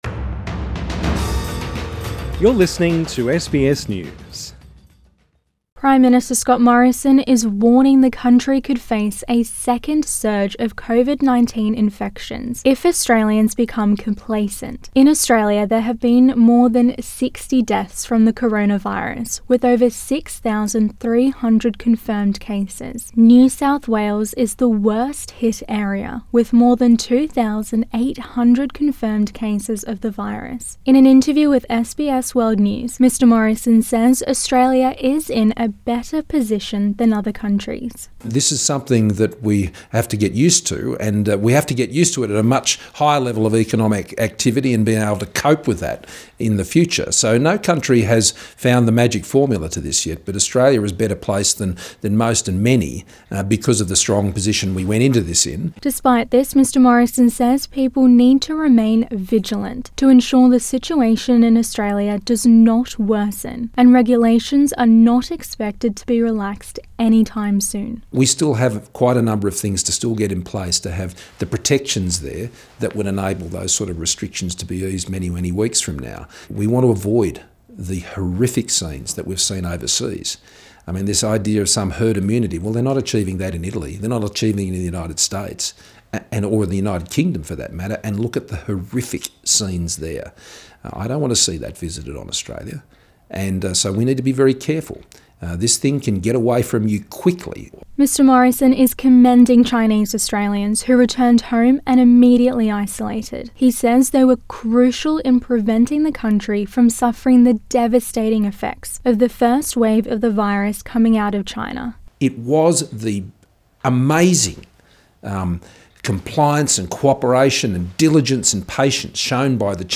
Prime Minister Scott Morrison speaking to SBS Source: SBS